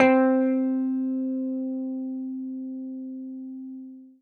samples / guitar-electric / C4.wav
C4.wav